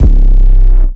archived music/fl studio/drumkits/goodtakimu drumkit/808s